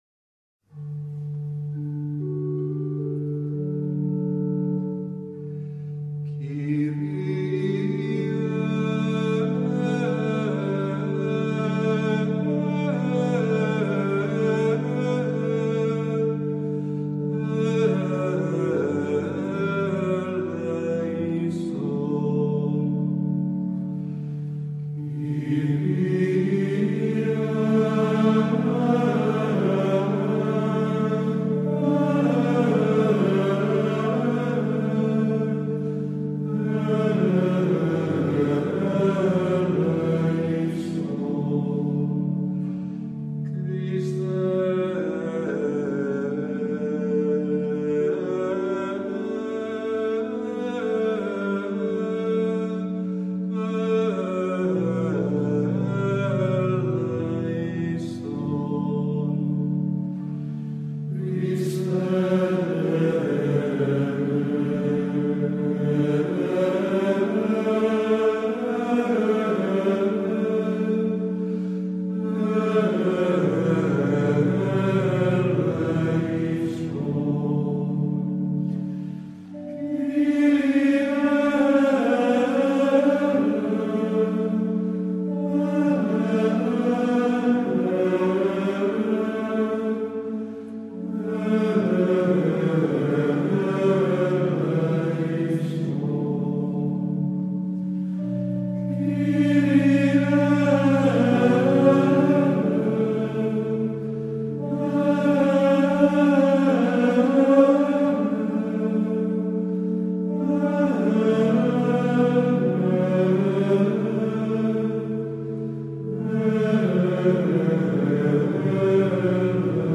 missa VIII de angelis - Kyrie.m4v.mp3